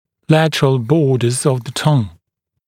[‘lætərəl ‘bɔːdəz əv ðə tʌŋ][‘лэтэрэл ‘бо:дэз ов зэ тан]боковые поверхности языка, боковые границы языка